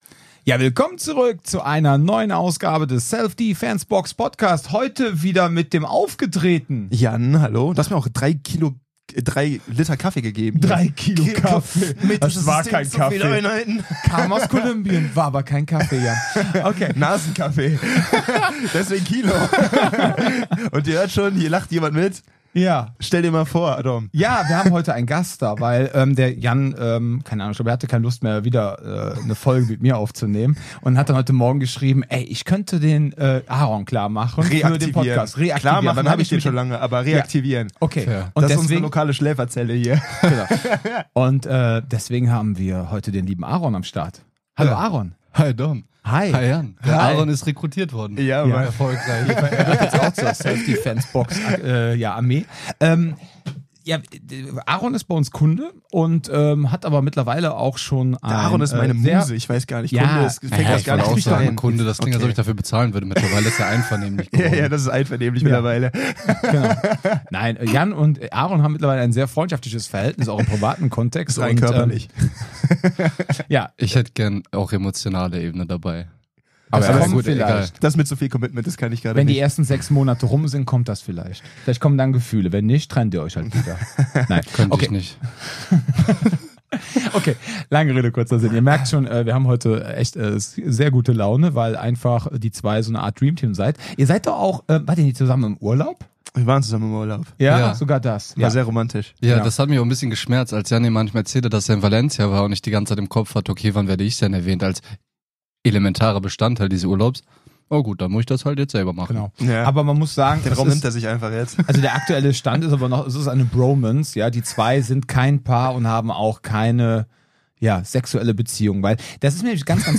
Beschreibung vor 1 Jahr Die aktuelle Episode des Selfdefensebox Podcast beginnt wie gewohnt mit einer Mischung aus Humor, Selbstironie und einer lockeren Atmosphäre.